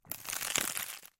Звуки салата